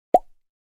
Pop 1.mp3